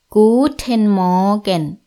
guten-morgen-frau.mp3